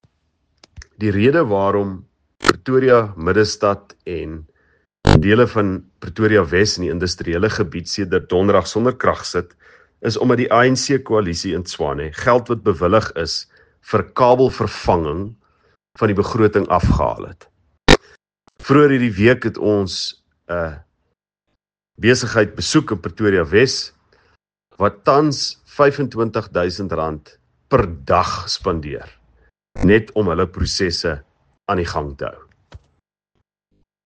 Afrikaans soundbites by Ald Cilliers Brink
Cilliers-Brink_AFR_Middestad-se-elektrisiteitsprobleme-agv-slegte-begrotingsbesluite.mp3